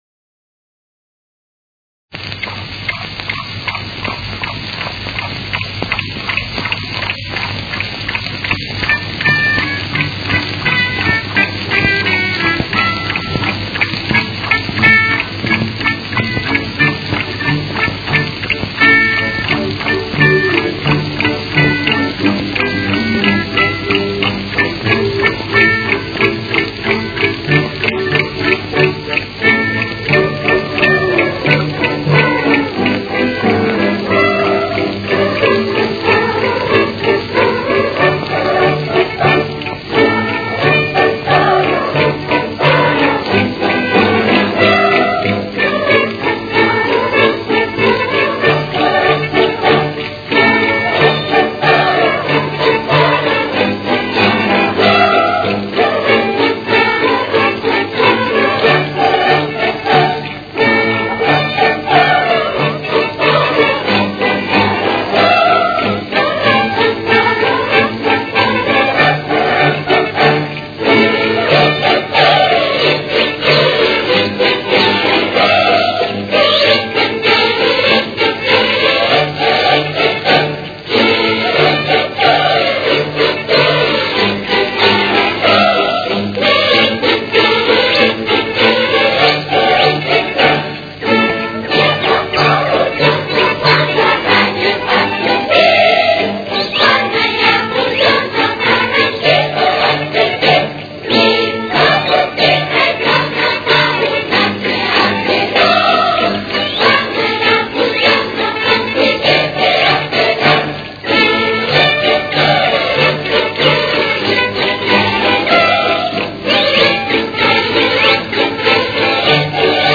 Ре мажор. Темп: 192.